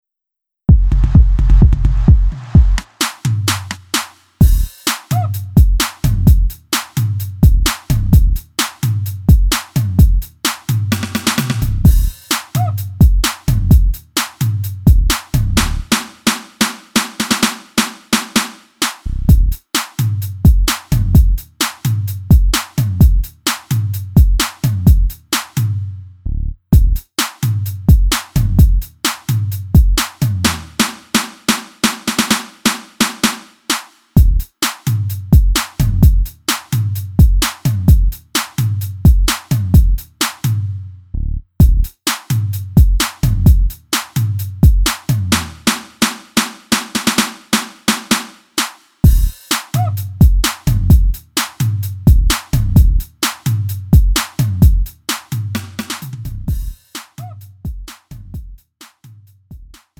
음정 -1키
장르 가요 구분 Lite MR